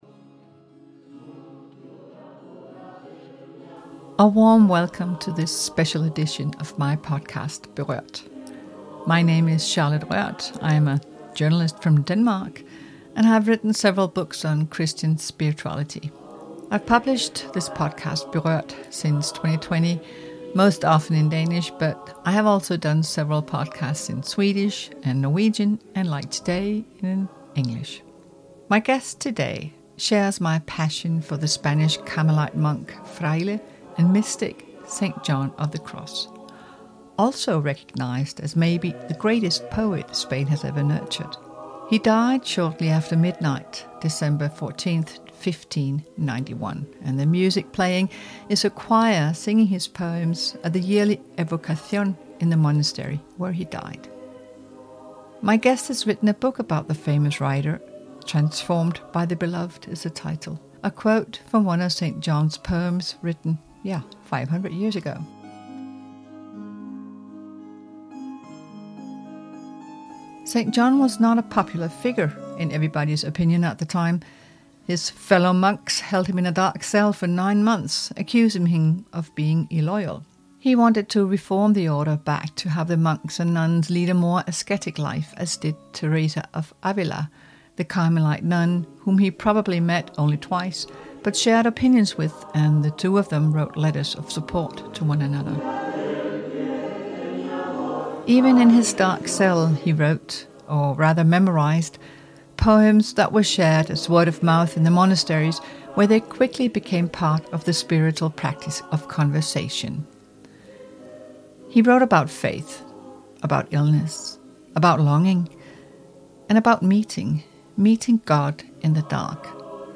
The podcast was recorded at the Carmelite Monastery in Ubeda, the new home of our John of the Cross sculpture from Los Olivos.